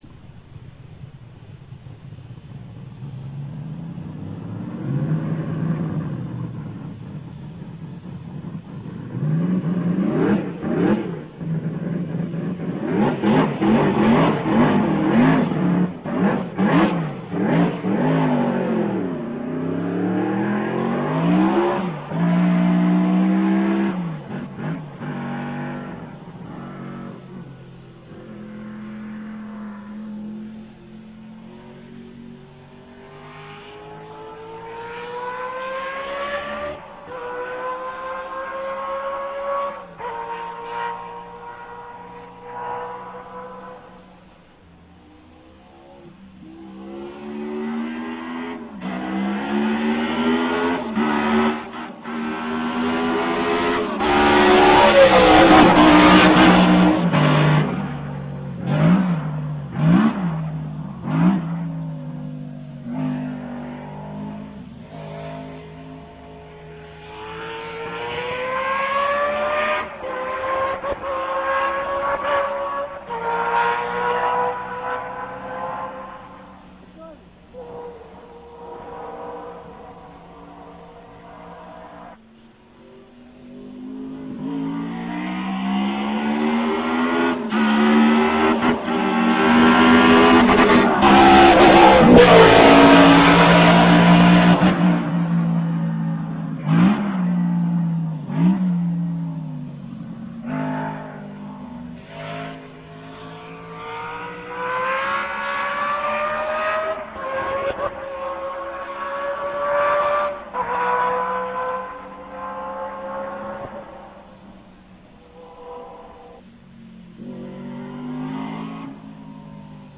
A jármű hangja általában hármas felosztásban hallható:
indulás, elhaladás, kocsiban ülve.
BRM_V16_Mk_2.rm